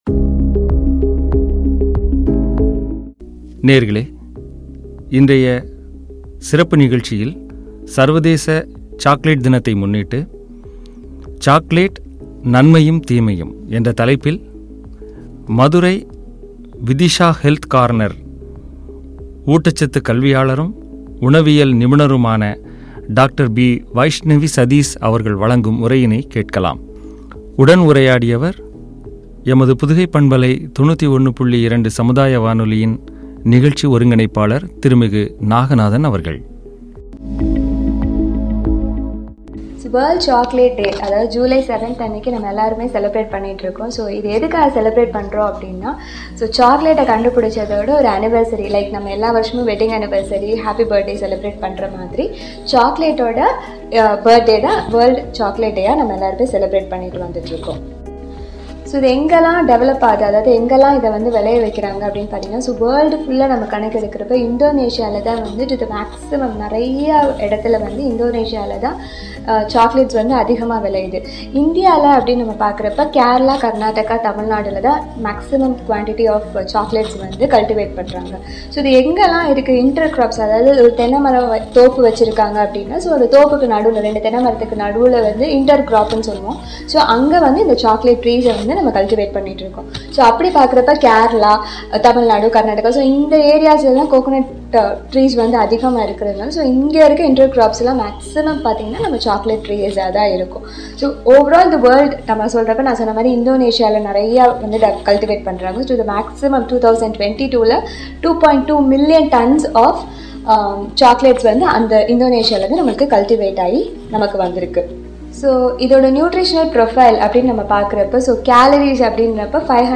நன்மையும் தீமையும்” பற்றிய உரையாடல்.